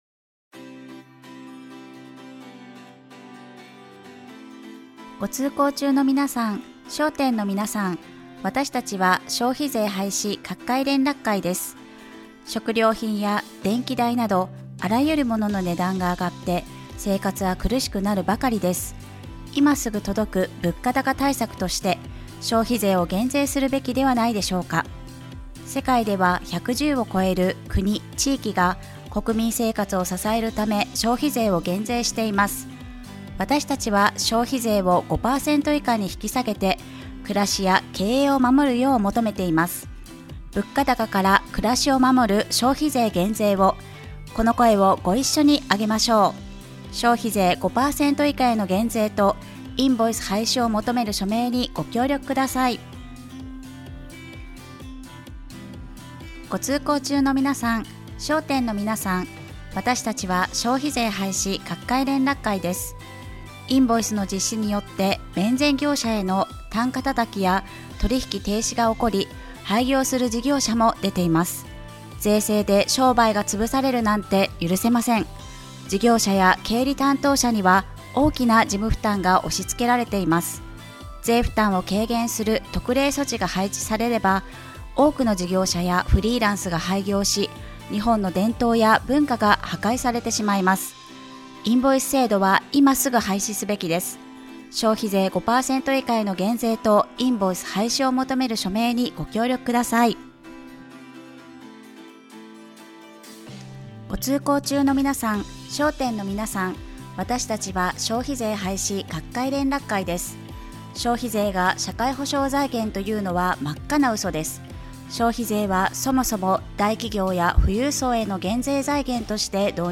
宣伝チラシ・宣伝スポット